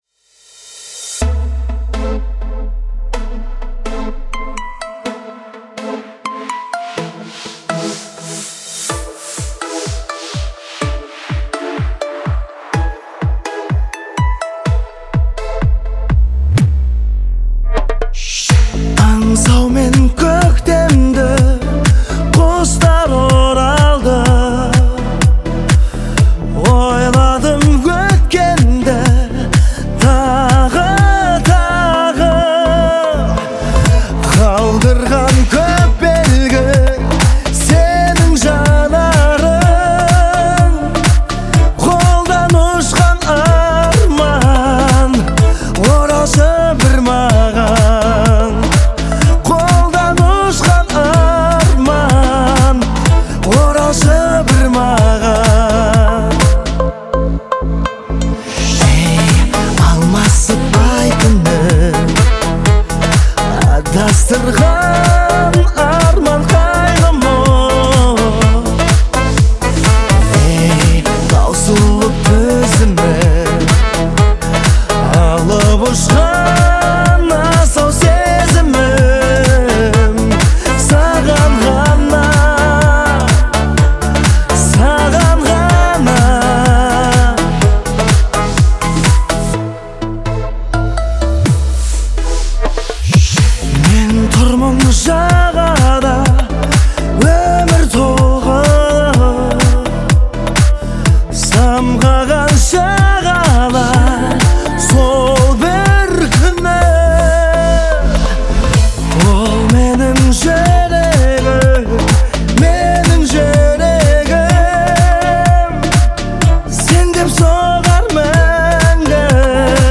голос проникает в самое сердце, создавая атмосферу нежности.